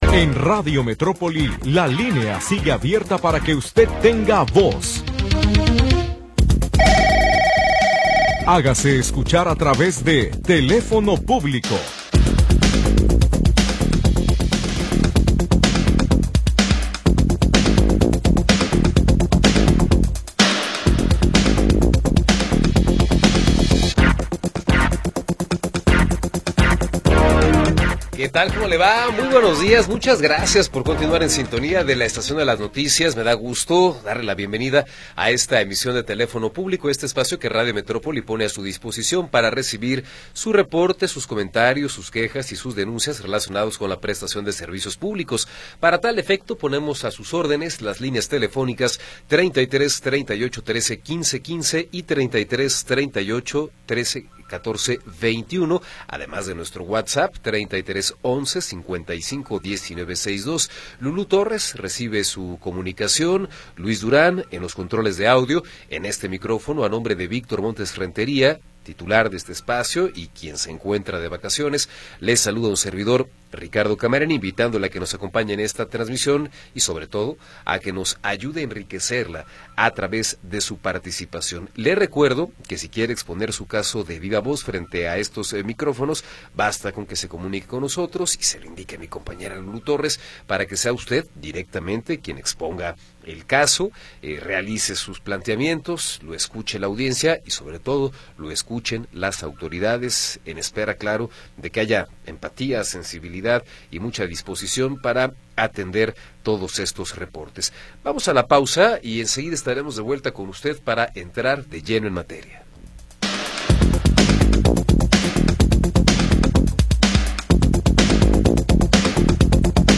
Programa transmitido el 28 de Julio de 2025.